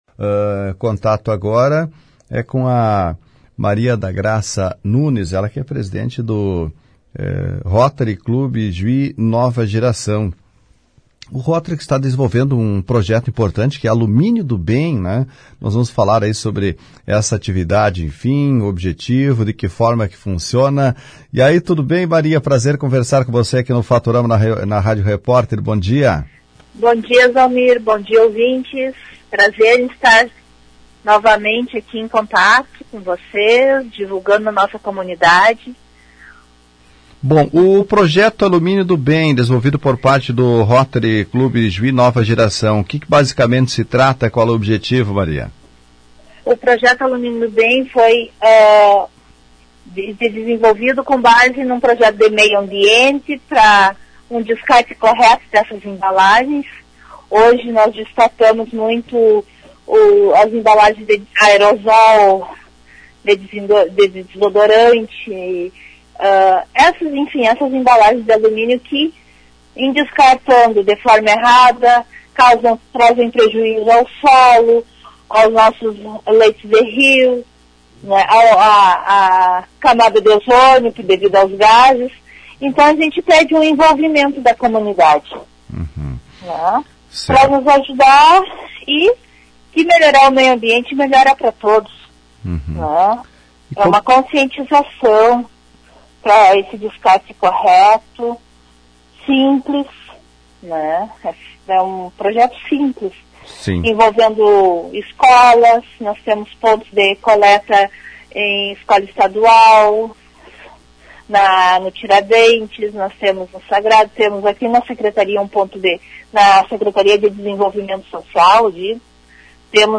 Falando ao jornalismo da mais popular, disse que os espaços para descarte desse tipo de material estão se multiplicando.